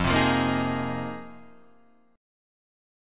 Fm11 chord